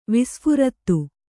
♪ visphurattu